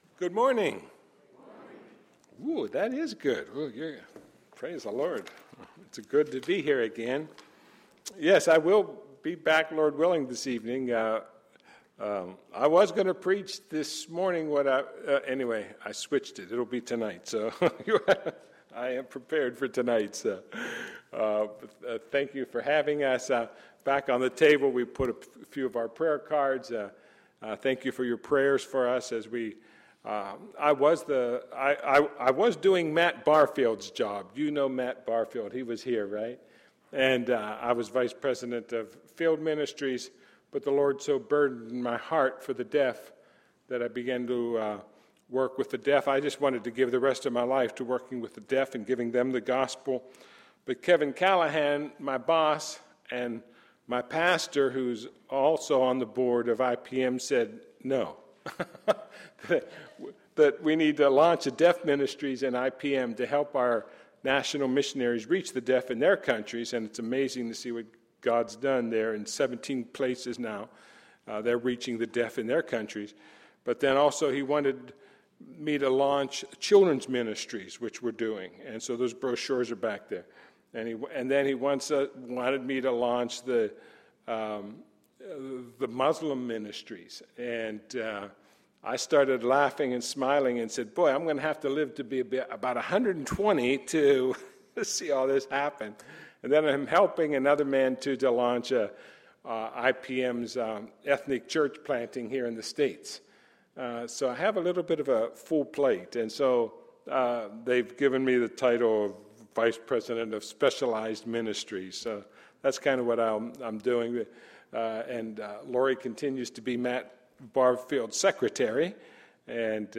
Sunday, May 20, 2012 – Morning Message